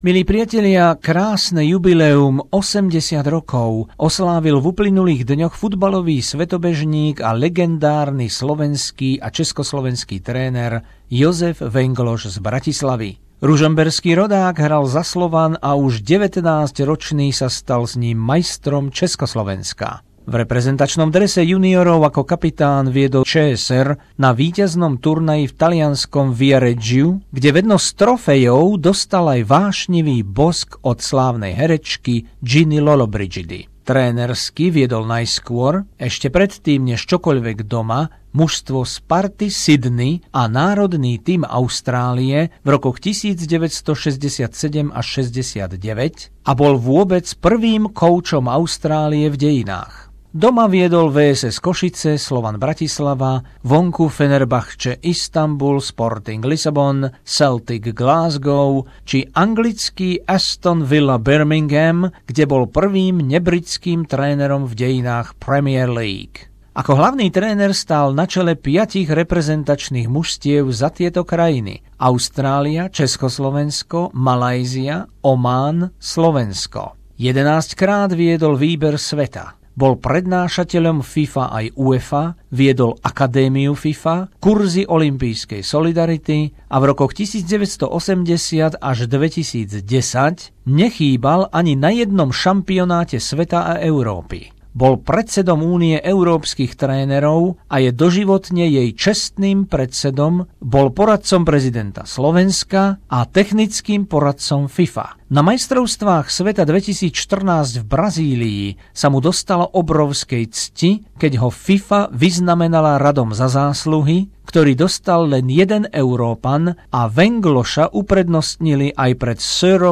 Rozhovor s najznámejším a najúspešnejším slovenským futbalovým trénerom Jozefom Venglošom z Bratislavy, ktorý sa dožil 80 rokov. Vengloš bol prvým reprezentačným trénerom Austrálie v dejinách vôbec